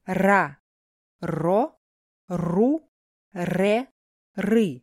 🔊 Hörprobe: Hartes Р in Silben РА [ra] РО [ro] РУ [ru] РЭ [re] РЫ [rɨ]
r-russisch-hart-silben.mp3